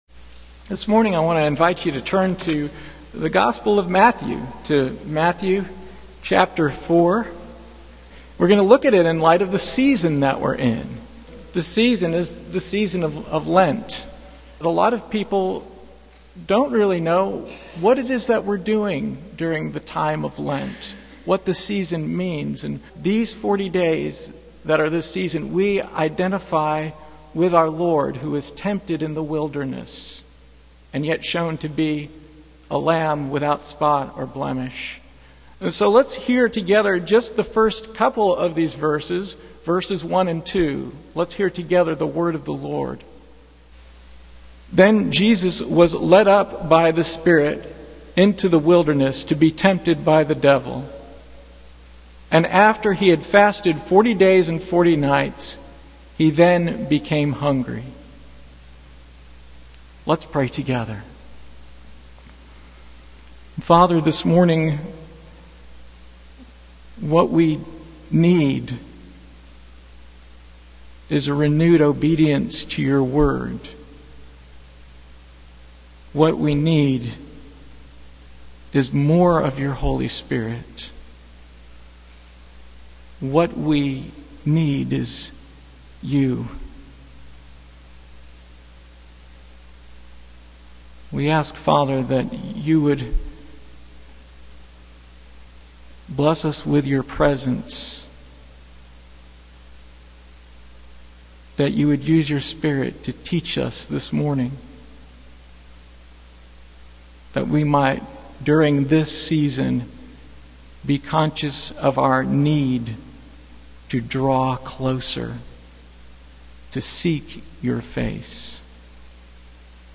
Passage: Matthew 4:1-2 Service Type: Sunday Morning